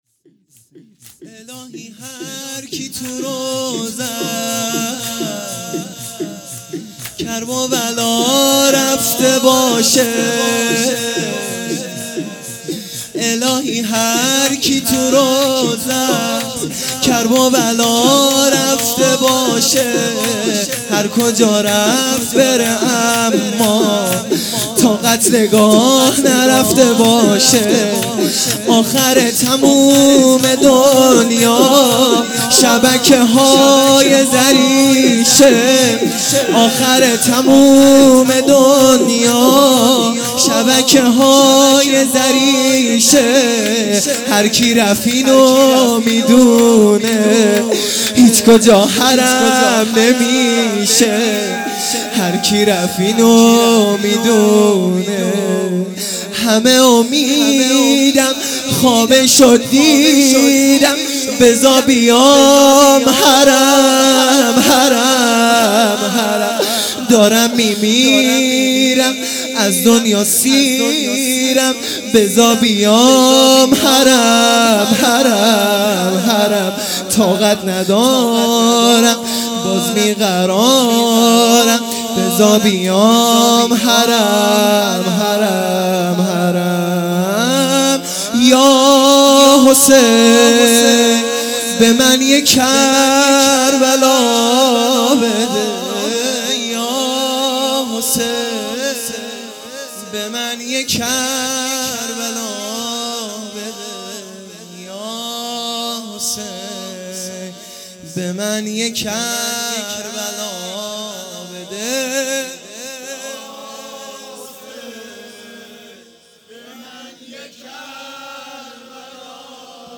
شوراحساسی ا الهی هرکی تو روضست